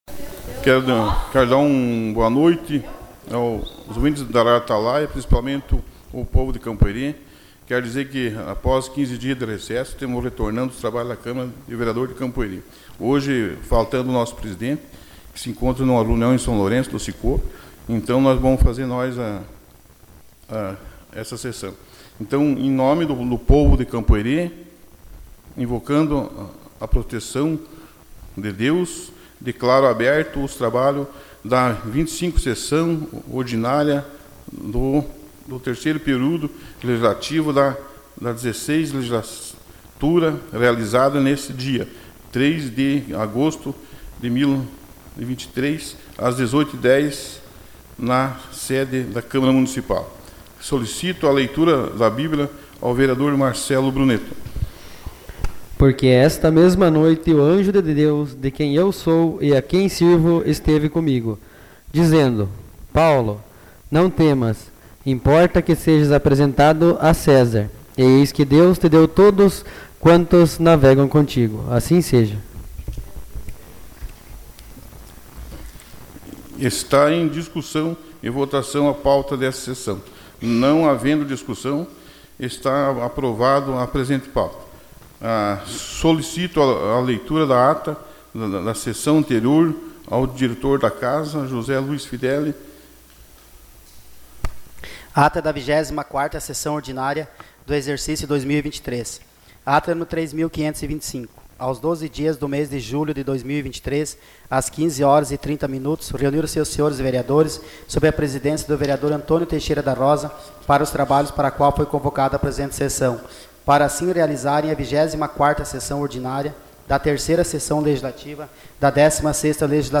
Sessão Ordinária 03/08